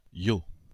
Llo (French pronunciation: [jo]